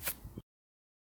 tbd-station-14/Resources/Audio/Effects/Footsteps/grass2.ogg at 0bbe335a3aec216e55e901b9d043de8b0d0c4db1
grass2.ogg